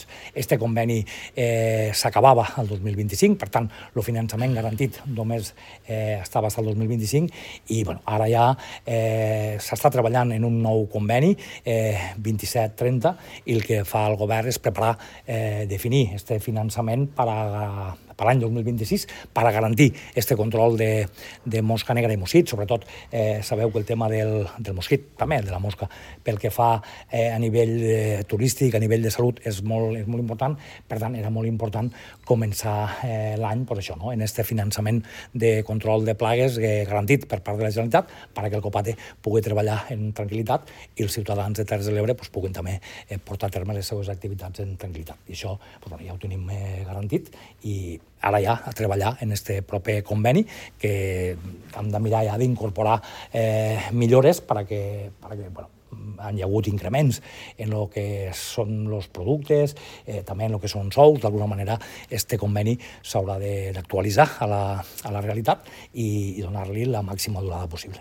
El delegat del Govern a les Terres de l’Ebre